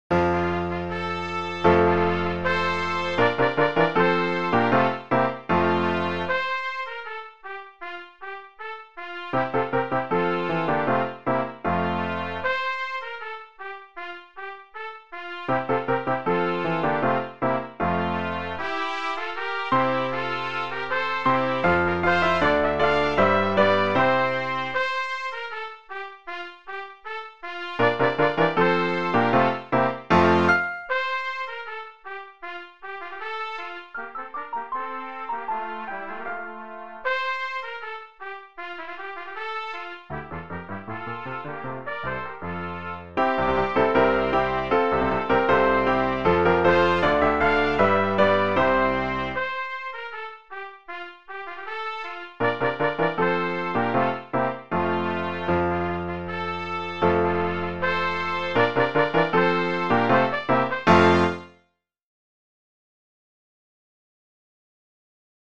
Trumpet Ensemble
optional piano accompaniment